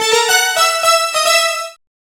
Synth Lick 49-12.wav